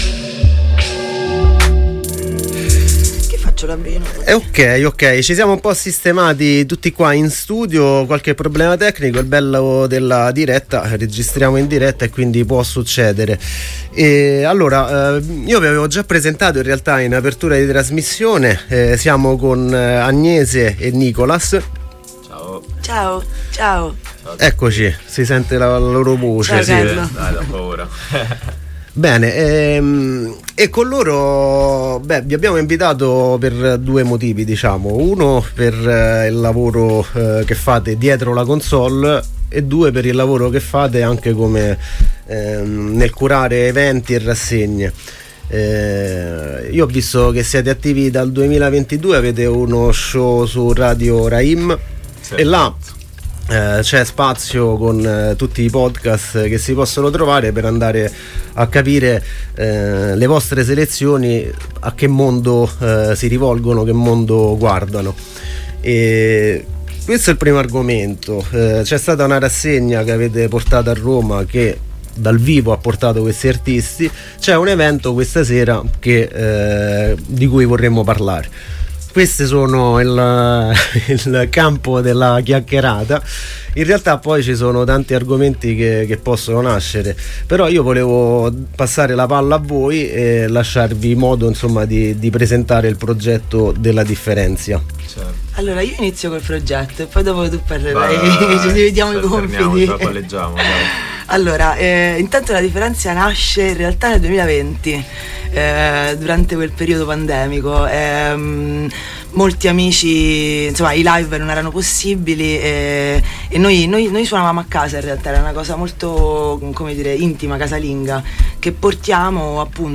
Intervista a La Diferencia | Radio Città Aperta
groovy-times-intervista-ladiferencia.mp3